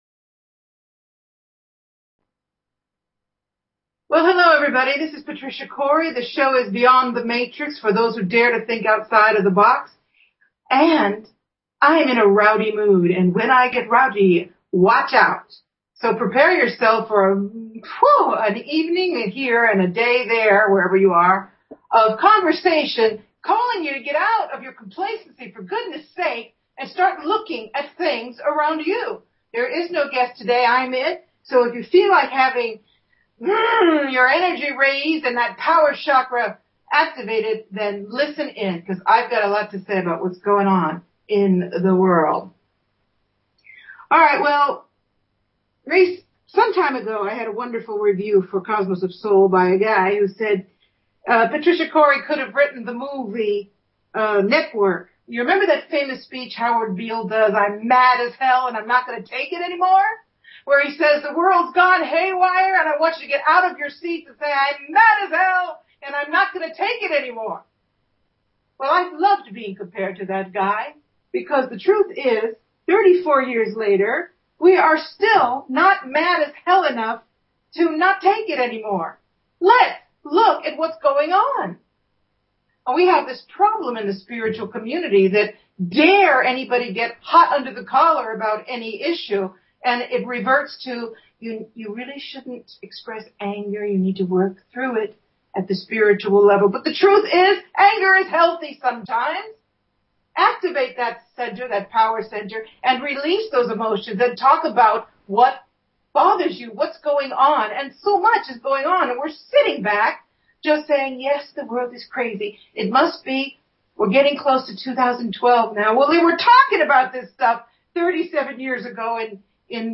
In today's fiery and informative show